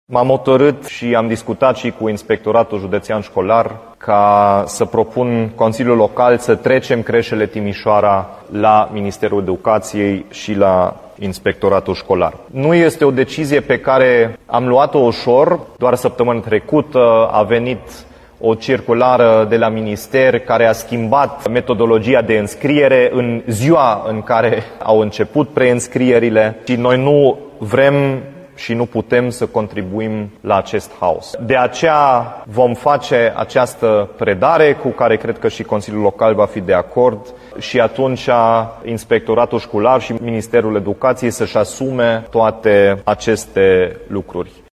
Primarul Dominic Fritz a anunțat că ar fi dorit păstrarea creșelor în gestiunea municipalității, dar din cauza haosului din sistem nu a putut face acest lucru.